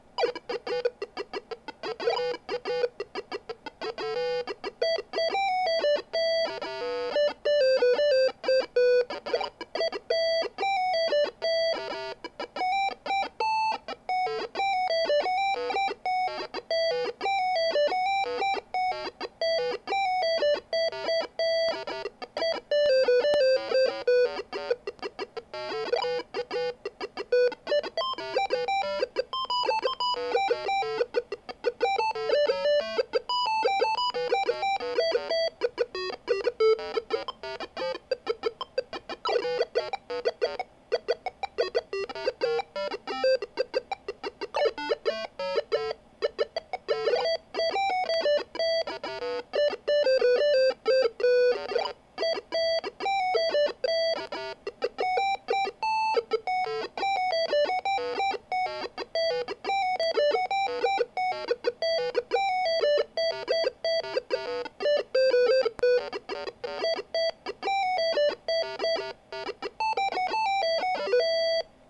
PC Speaker